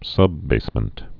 (sŭbbāsmənt)